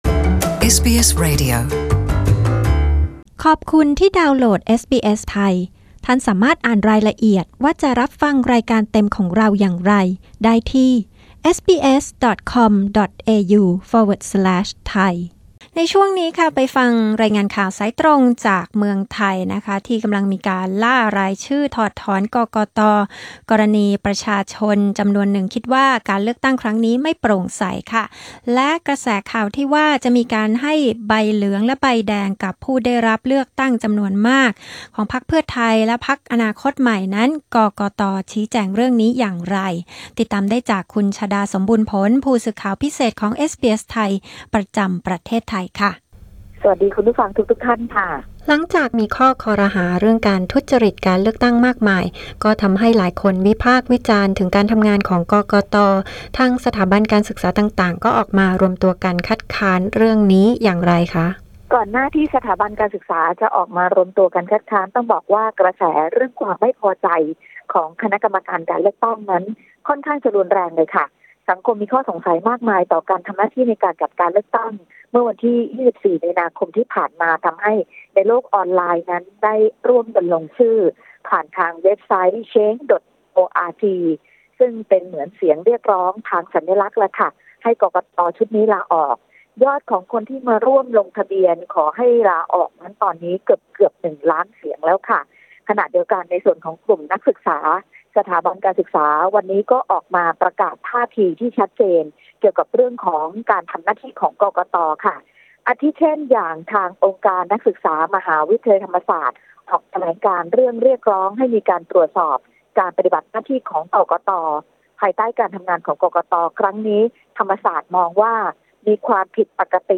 รายงานนี้ออกอากาศเมื่อคืนวันพฤหัสบดี ที่ 28 มี.ค. ในรายการวิทยุเอสบีเอส ไทย เวลา 22.00 น.